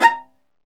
Index of /90_sSampleCDs/Roland - String Master Series/STR_Viola Solo/STR_Vla2 _ marc